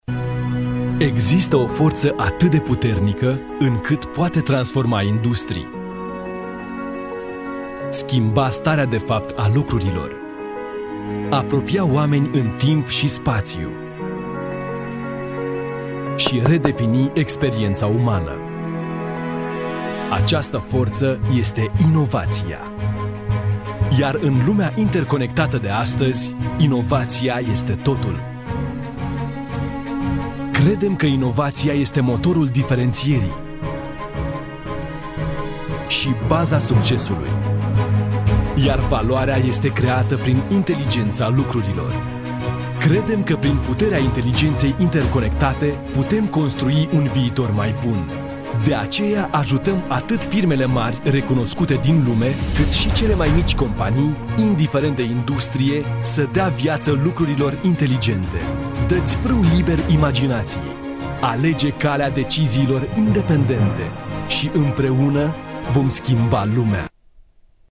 罗马尼亚语中年大气浑厚磁性 、沉稳 、娓娓道来 、科技感 、积极向上 、时尚活力 、素人 、男专题片 、宣传片 、纪录片 、广告 、飞碟说/MG 、课件PPT 、工程介绍 、绘本故事 、动漫动画游戏影视 、250元/百单词男罗04 罗马尼亚语男声 医疗行业MG动画 大气浑厚磁性|沉稳|娓娓道来|科技感|积极向上|时尚活力|素人